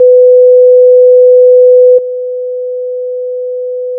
- 空気伝播音の場合 -
《基準壁》からの音 2秒 →《基準壁+ノイズクリア》からの音 2秒